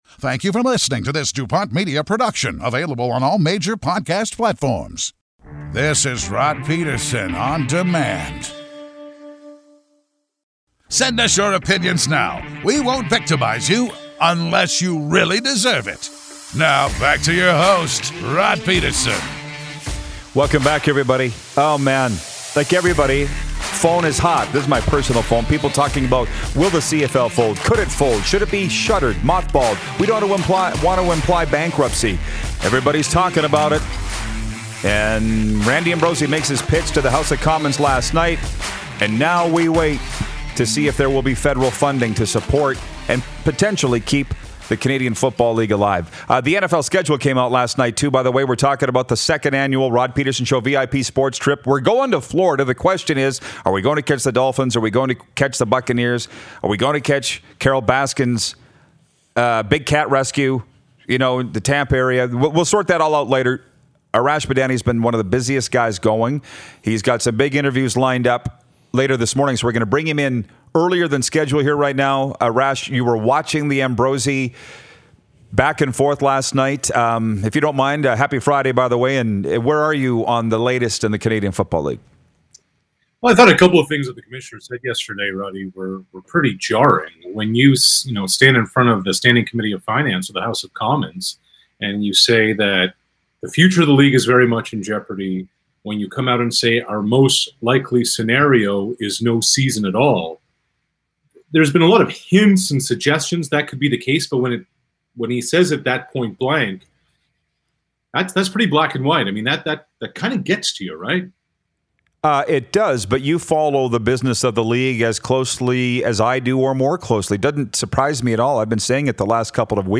It’s a fantastic Football Friday featuring a fiery Frenzy and a flurry of friends! Arash Madani, Sportsnet Insider, joins us on the Video Chat!